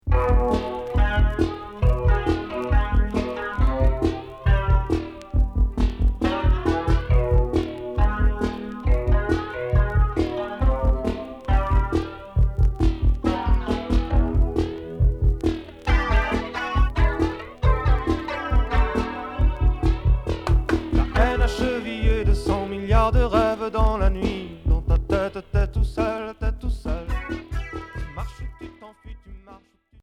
Pop experimentale